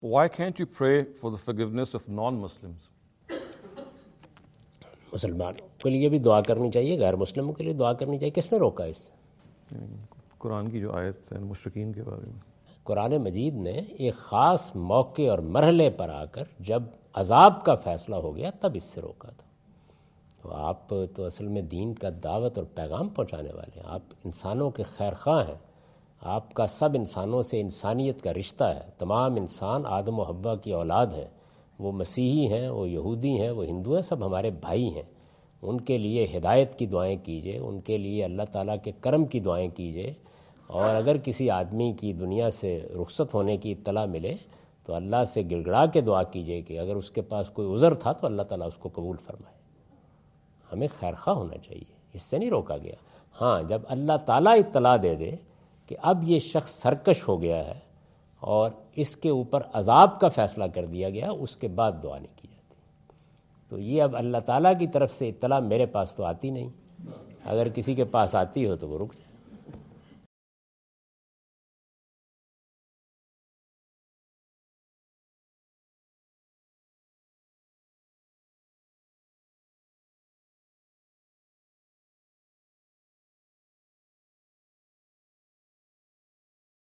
In this video Javed Ahmad Ghamidi answer the question about "why can't we pray for non-Muslims?" asked at East-West University Chicago on September 24,2017.
جاوید احمد غامدی اپنے دورہ امریکہ2017 کے دوران شکاگو میں "ہم غیر مسلموں کے لیے دعا کیوں نہیں کر سکتے؟" سے متعلق ایک سوال کا جواب دے رہے ہیں۔